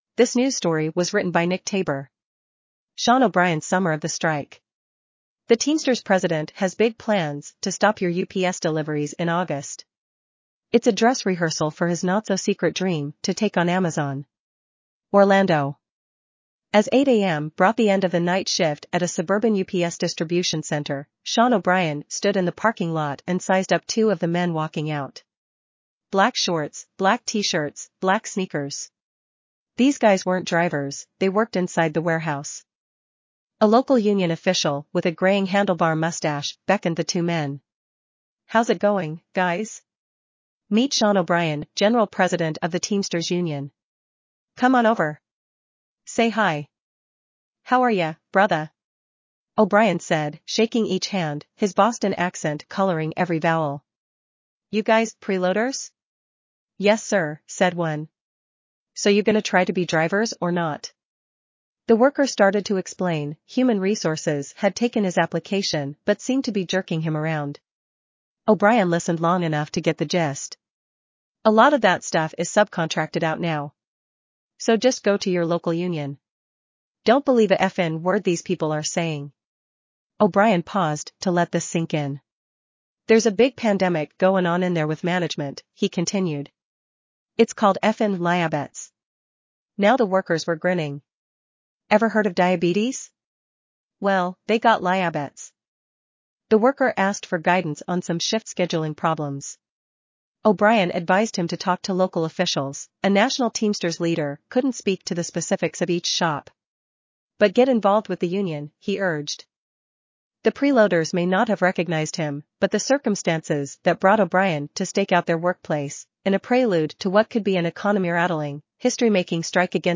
azure_en-US_en-US-JennyNeural_standard_audio.mp3